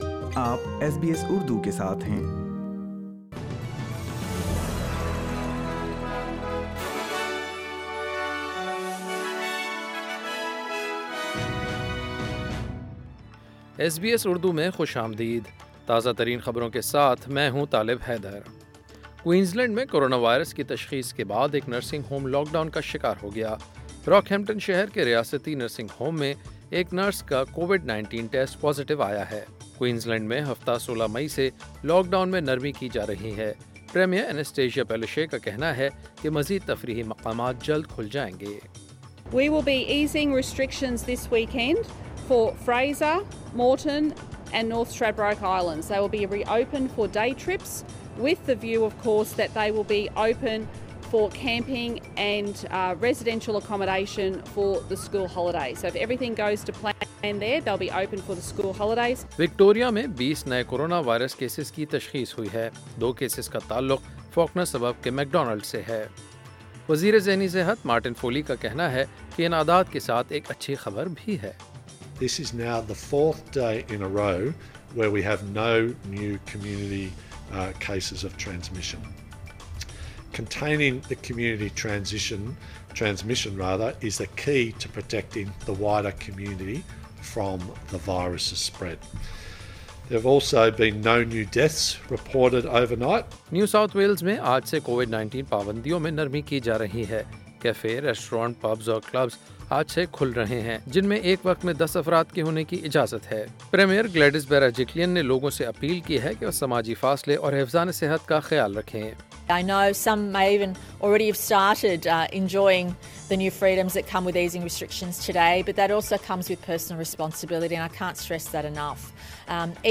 SBS Urdu News 15 May 2020